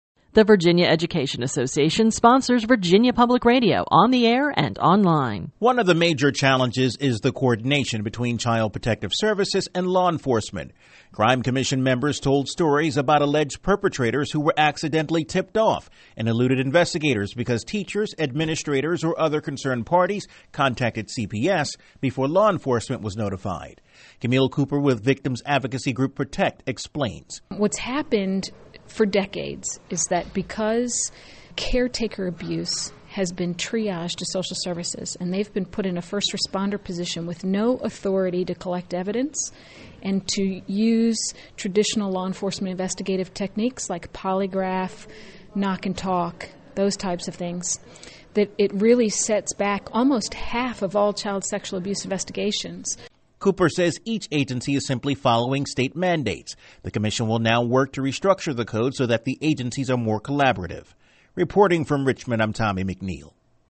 This entry was posted on July 25, 2013, 4:42 pm and is filed under Daily Capitol News Updates.